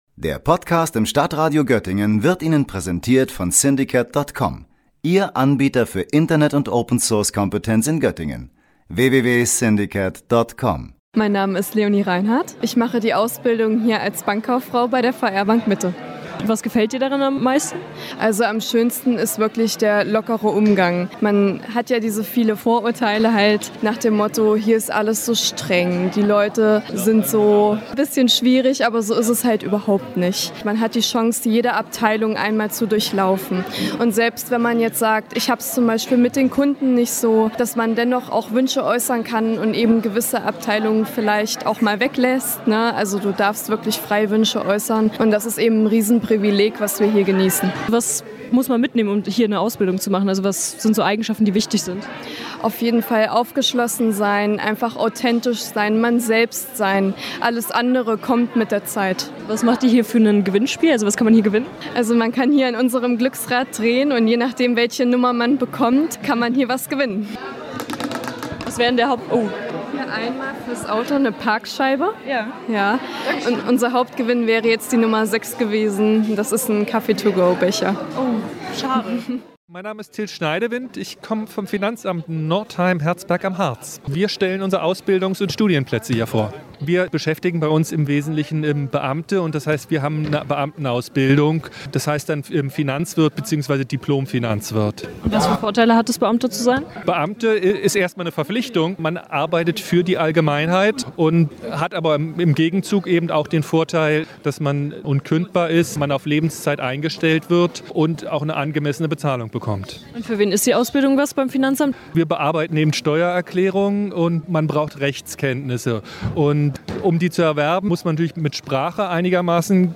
Am vergangenen Freitag fand in Duderstadt die Eichsfelder Berufsmesse statt. Viele Betriebe waren dabei vor Ort und haben allen Interessierten Chancen für einen möglichen Berufseinstieg aufgezeigt.